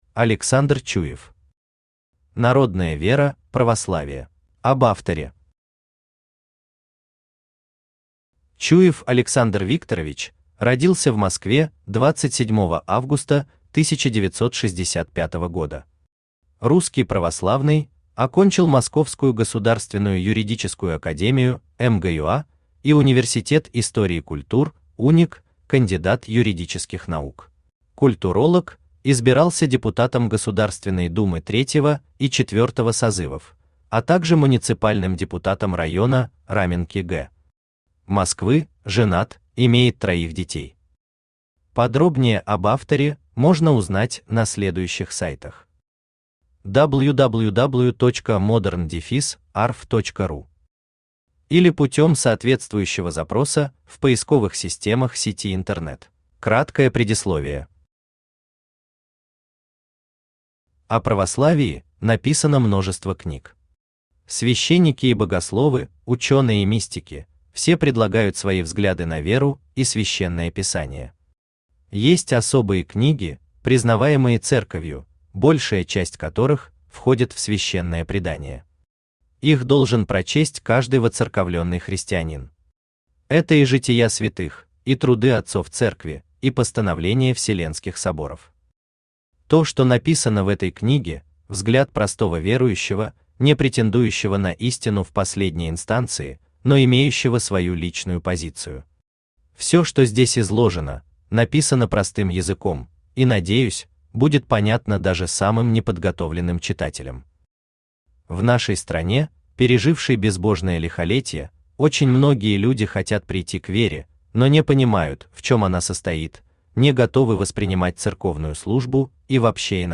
Аудиокнига Народная вера Православие | Библиотека аудиокниг
Aудиокнига Народная вера Православие Автор Александр Викторович Чуев Читает аудиокнигу Авточтец ЛитРес.